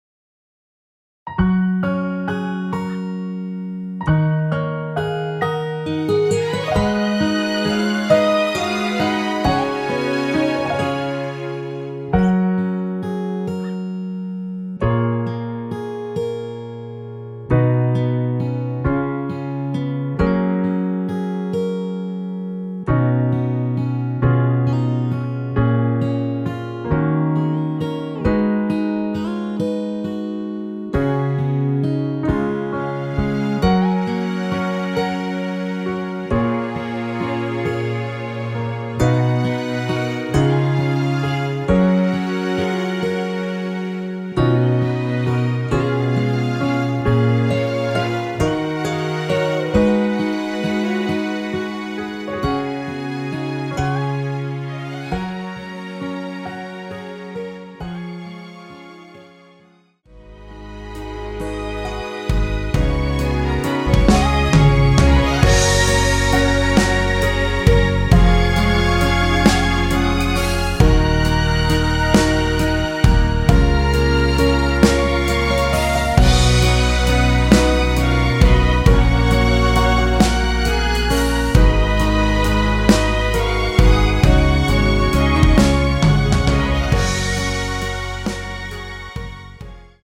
원키에서(+6)올린 MR입니다.
F#
앞부분30초, 뒷부분30초씩 편집해서 올려 드리고 있습니다.
중간에 음이 끈어지고 다시 나오는 이유는